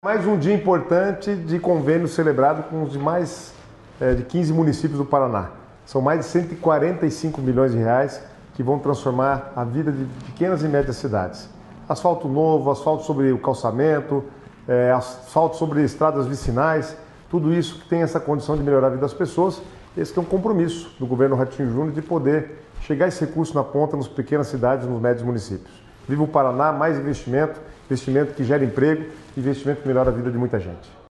Sonora do secretário das Cidades, Guto Silva, sobre as novas liberações para pavimentações em todas as regiões do Estado